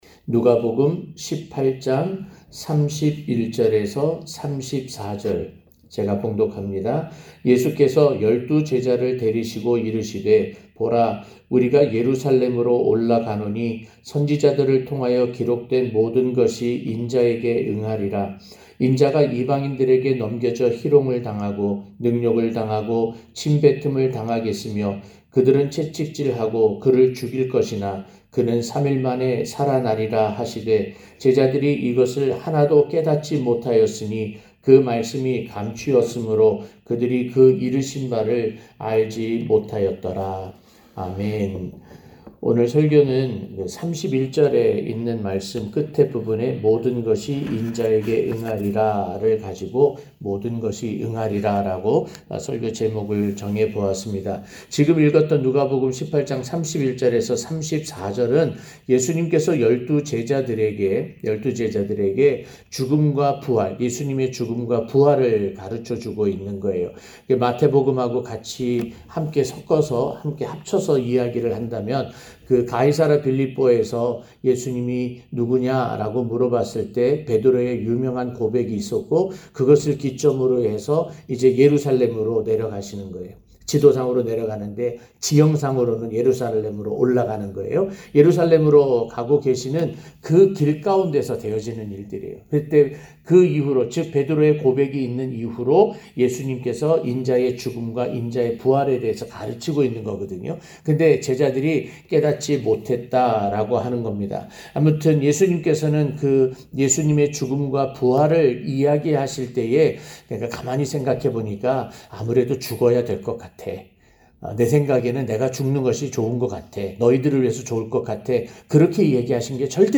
고난주간설교 5-모든 것이 응하리라(눅 18:31-34)